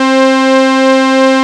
THICK WAVE 1.wav